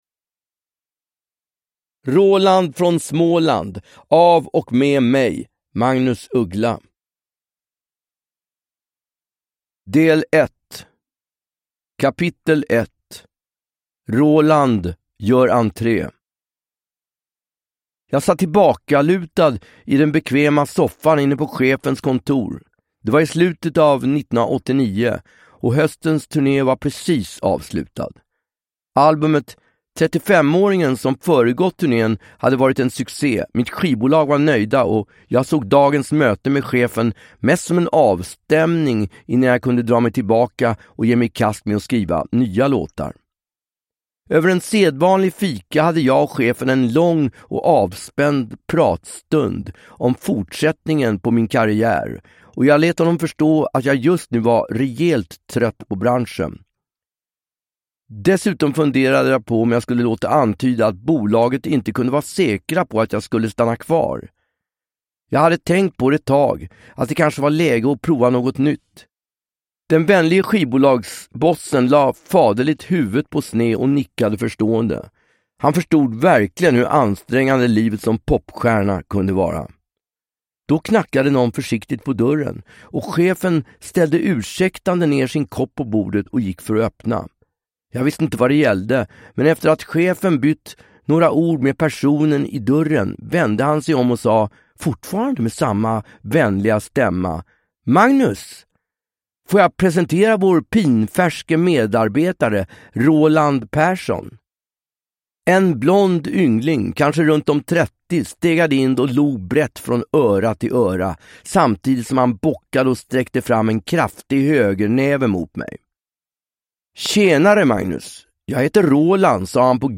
Roland från Småland – Ljudbok
Uppläsare: Magnus Uggla